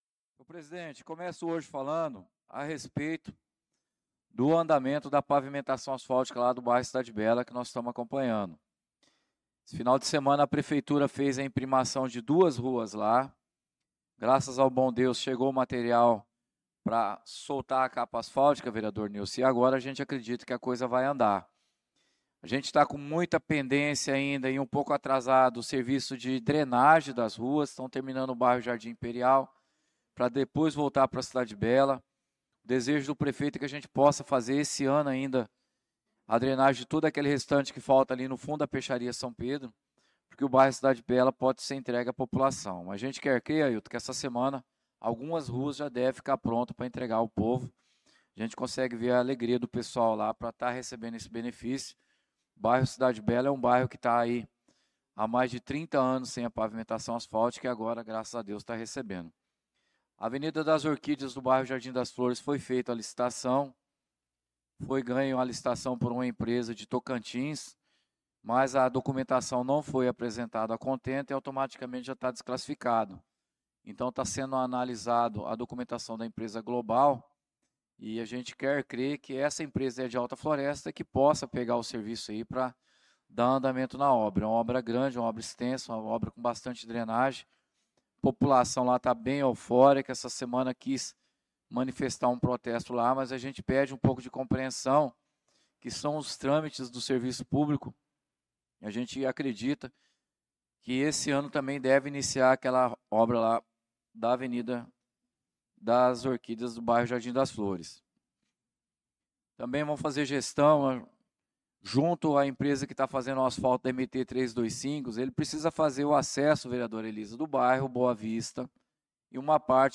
Pronunciamento do vereador Claudinei de Jesus na Sessão Ordinária do dia 04/08/2025.